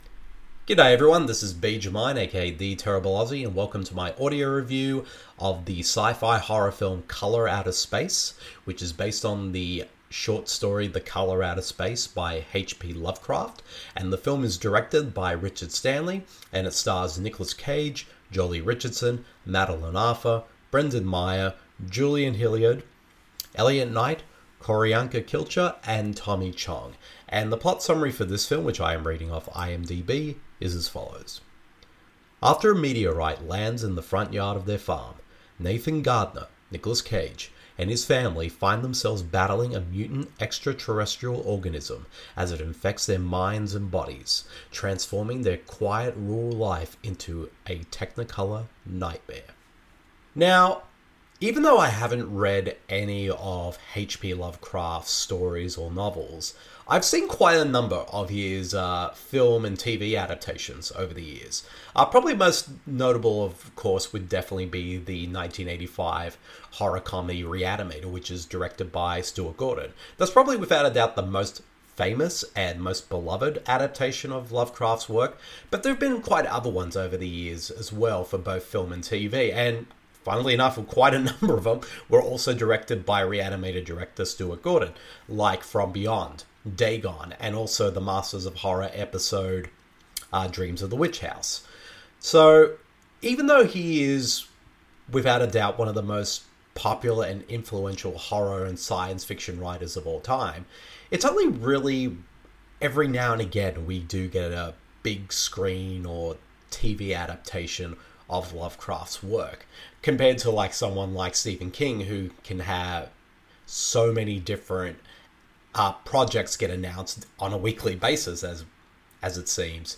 After a long 27 year break from making feature films, Richard Stanley has decided for his long-awaited return to the big screen by adapting H.P. Lovecraft’s famous short story COLOR OUT OF SPACE into a film starring Oscar-winning actor Nicolas Cage. The following review of the film is in an audio format.